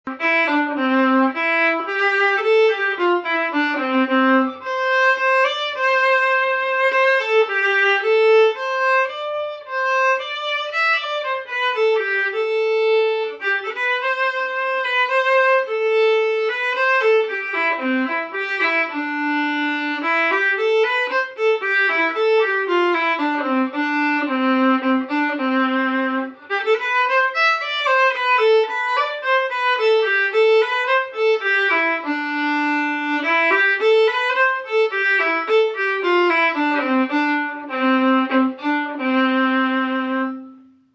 Key: C*
Form: Air (3/4)
Genre/Style: Irish air